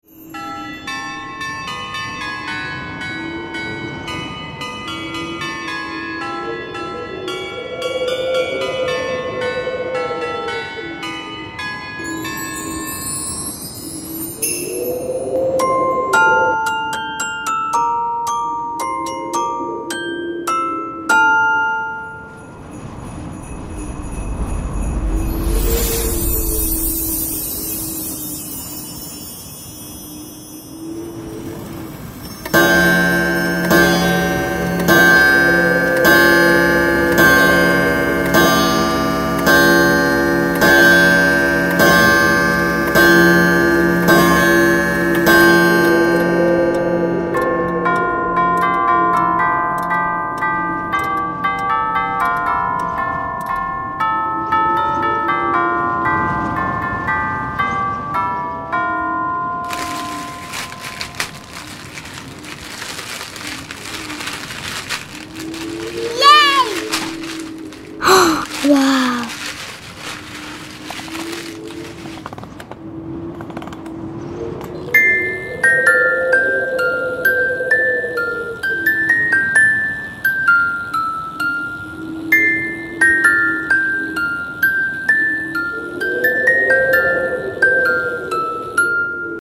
Звуки, которые можно услышать только на праздниках и различных мероприятиях для монтажа видео в mp3.